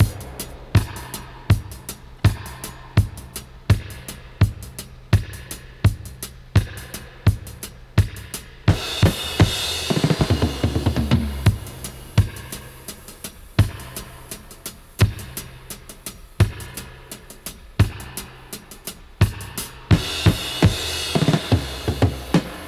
85-DUB-01.wav